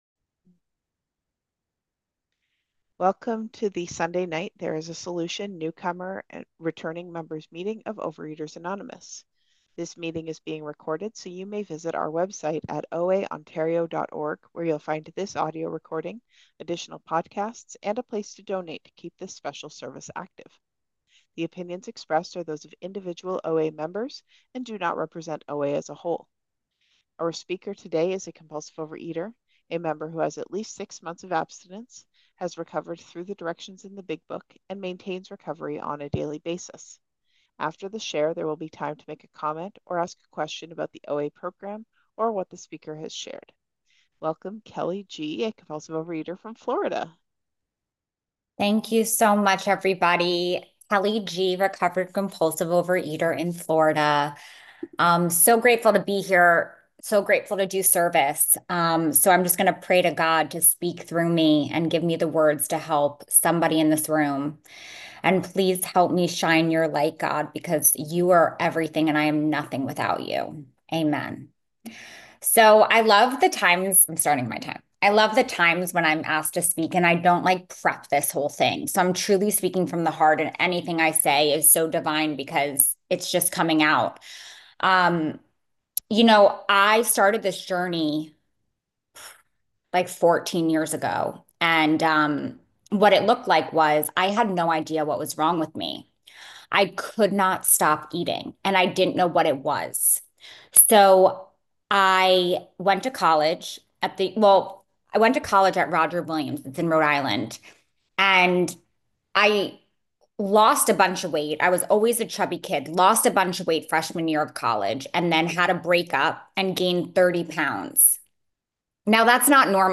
OA Newcomer Meeting